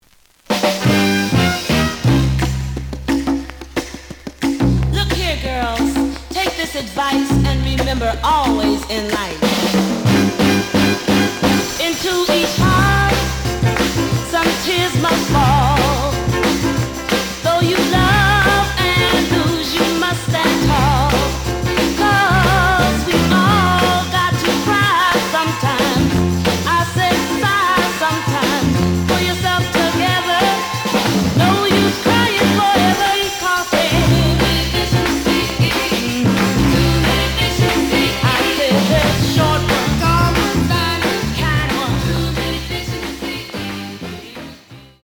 The audio sample is recorded from the actual item.
●Genre: Soul, 60's Soul
Some click noise on A side due to scratches.